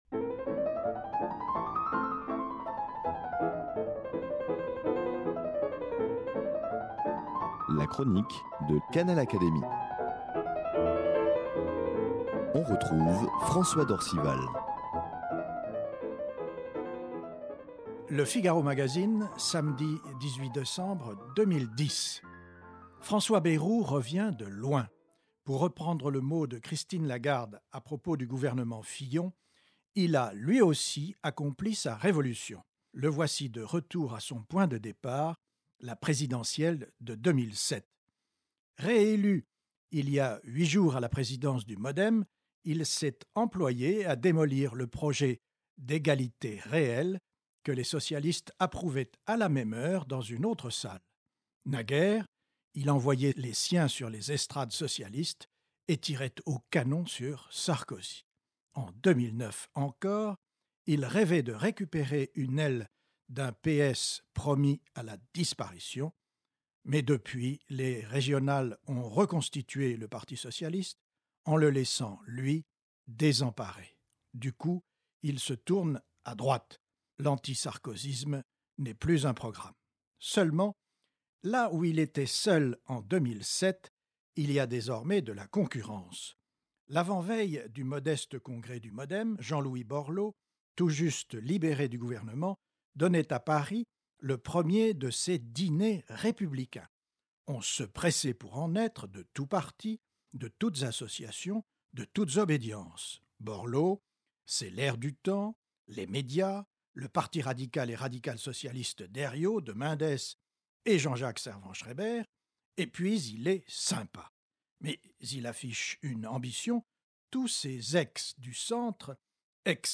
François d’Orcival reprend ici, au micro de Canal Académie, la chronique qu’il donne, le samedi, dans Le Figaro Magazine. S’il aborde plus volontiers des questions de société ou des sujets relatifs à l’actualité internationale, il n’hésite pas à traiter certains aspects de la politique française.
Elle est reprise ici par son auteur, avec l’aimable autorisation de l’hebdomadaire.